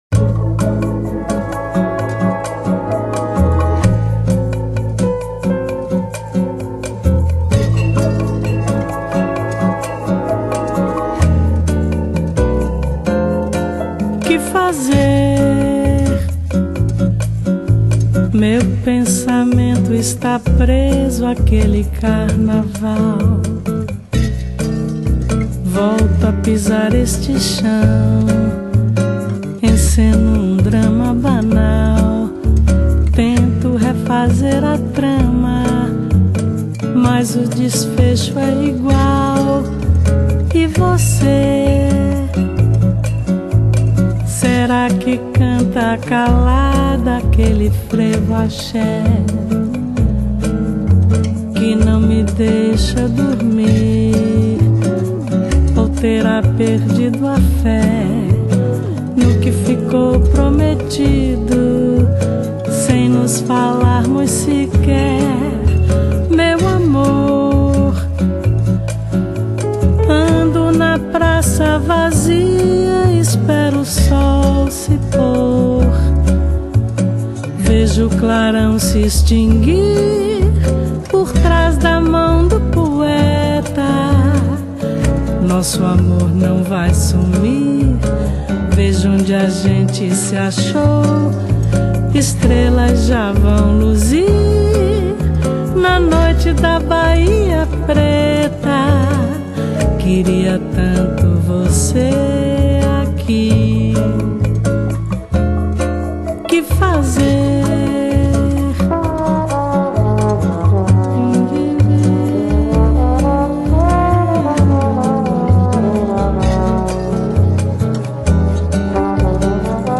由巴莎諾瓦一流大師演繹，在bossa nova與爵士結合的樂風舒適流暢地帶領聽者悠遊其中，享受恬靜的片刻與浪漫情懷。